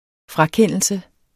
Udtale [ ˈfʁɑˌkεnˀəlsə ]